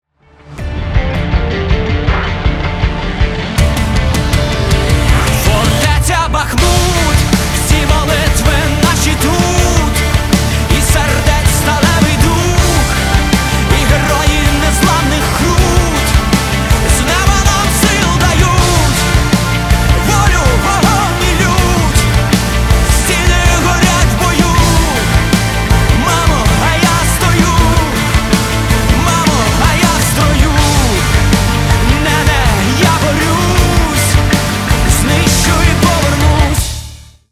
• Качество: 320, Stereo
мужской вокал
громкие
Драйвовые
мелодичные
Alternative Rock
украинский рок